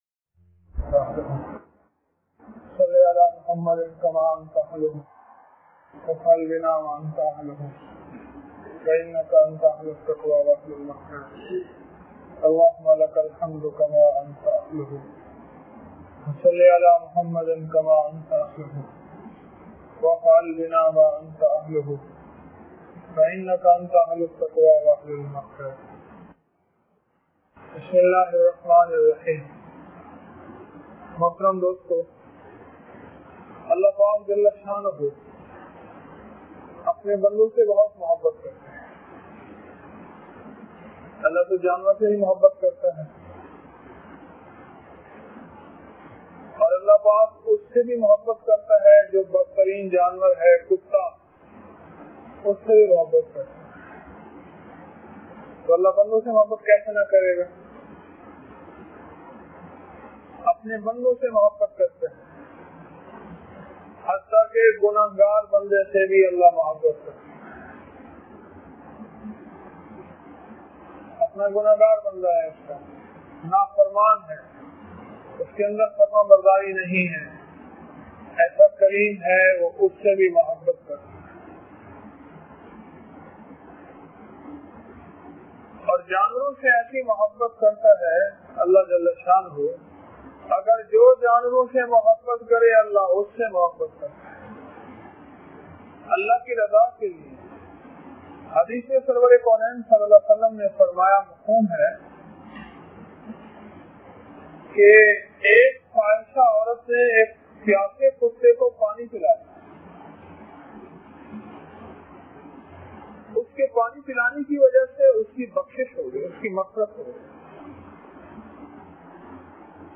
درس روحانیت وامن - 22 جون 2004